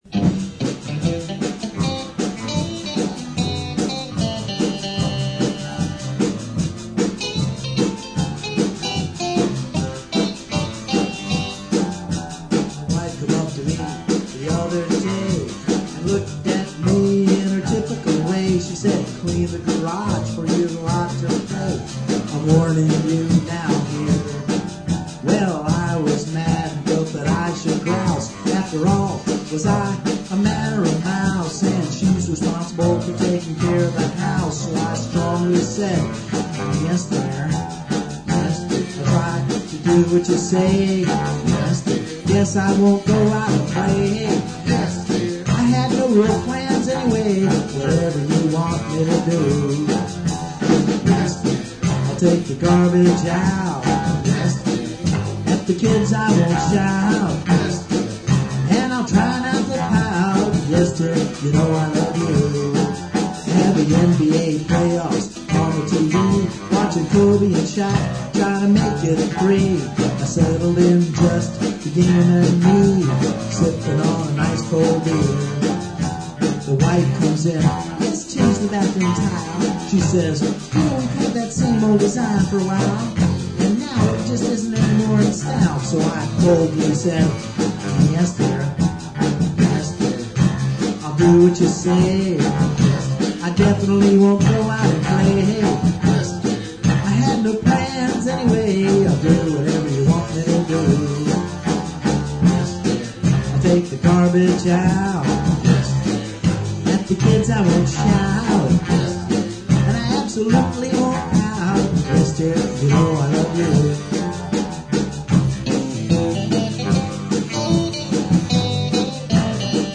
Rough Demo Version of a novelty song based on an inside joke with my wife of 40 years.